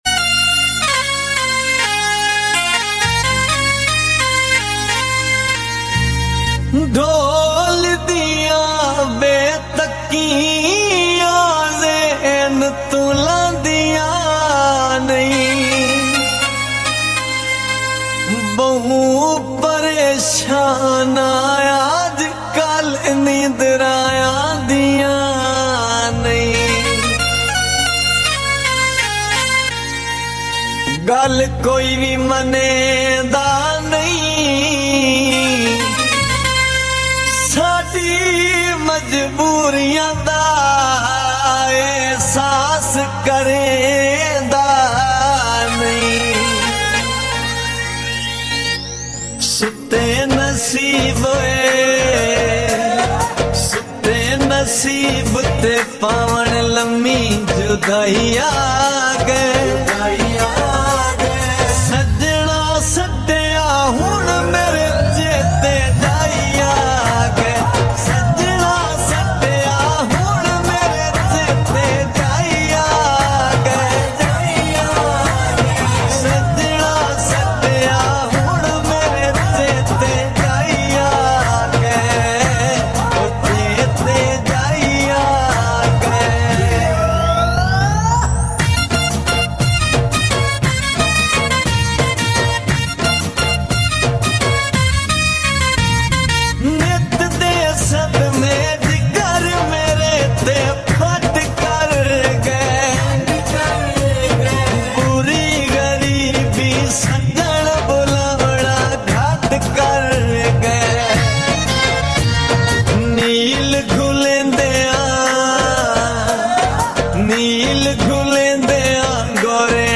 SLOWED AND REVERB